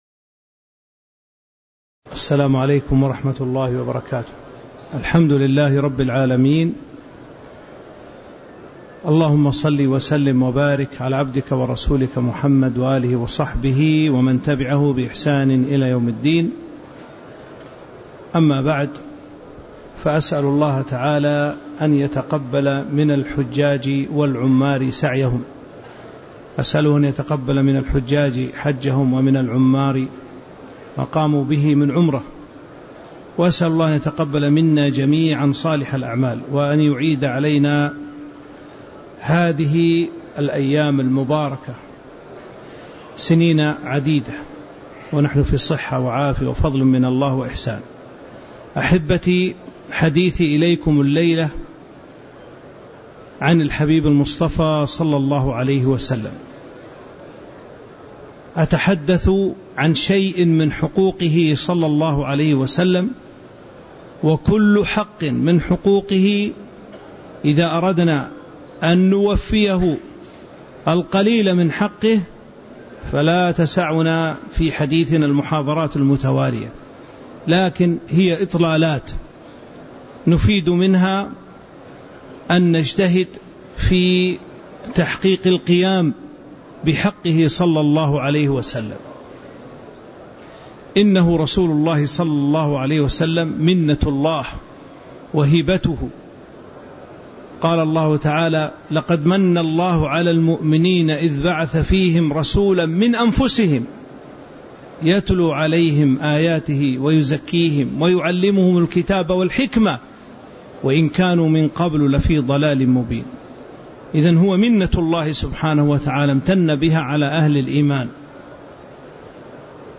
تاريخ النشر ١٦ ذو الحجة ١٤٤٥ هـ المكان: المسجد النبوي الشيخ